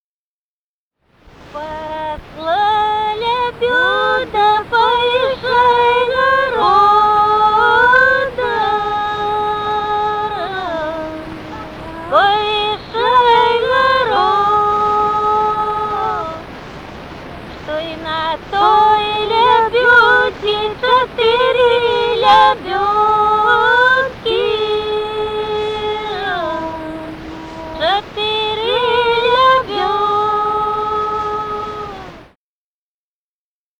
| diskname = Музыкальный фольклор Климовского района
Записали участники экспедиции